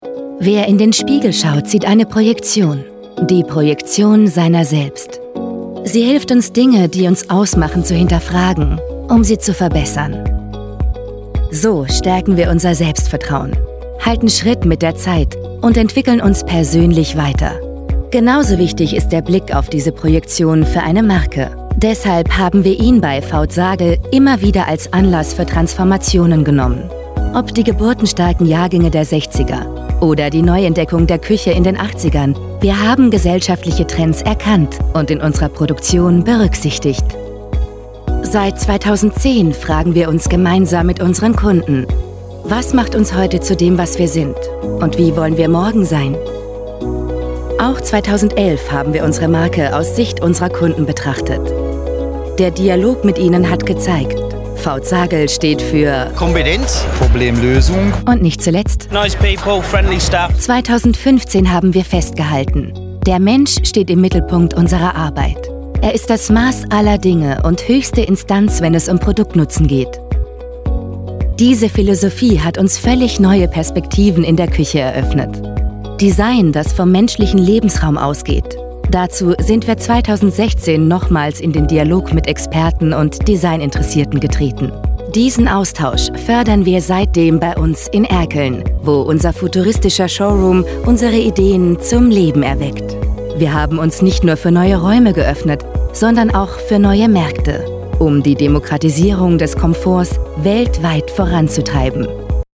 Sprechprobe: Industrie (Muttersprache):
Vauth Sagel_Imagefilm.mp3